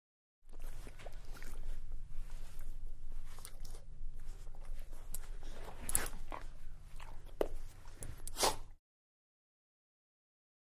DogLabradorLicks AT022001
Dog, Golden Labrador Licks And Breaths With Sharp Exhale At Tail. Close Perspective.